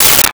Space Gun 05
Space Gun 05.wav